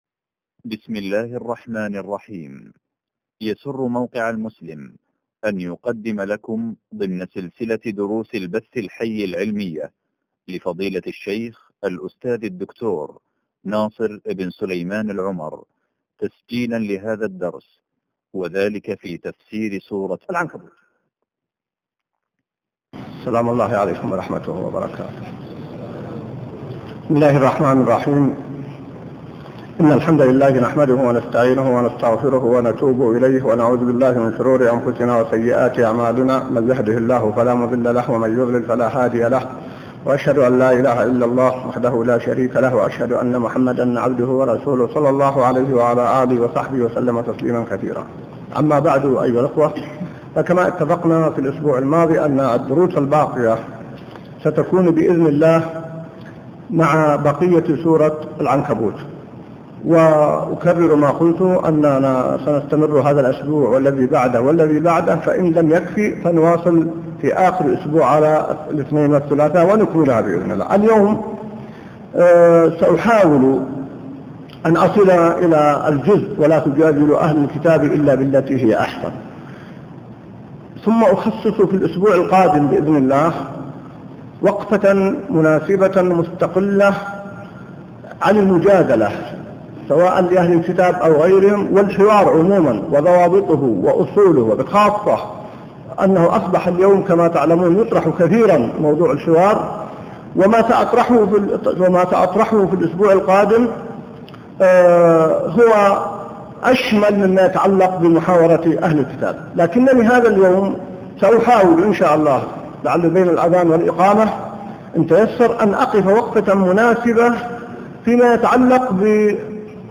الدرس 62 من تفسير سورة العنكبوت | موقع المسلم